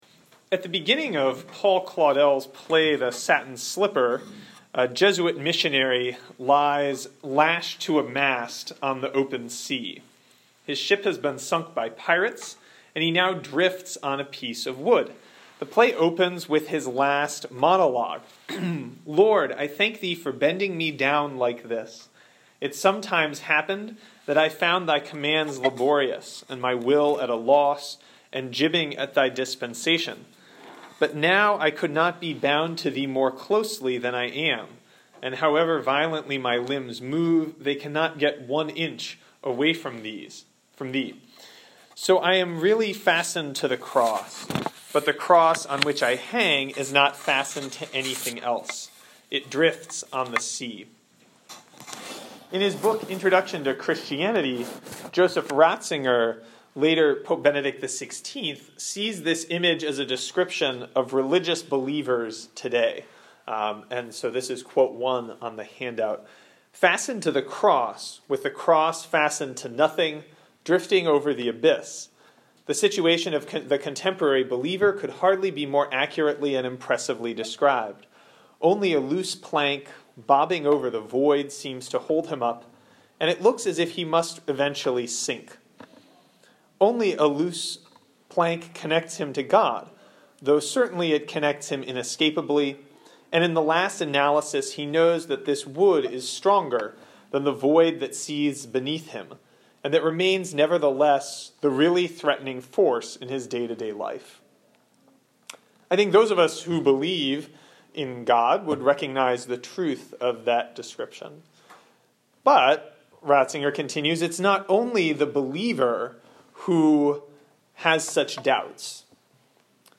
This talk was given at our chapter at West Point.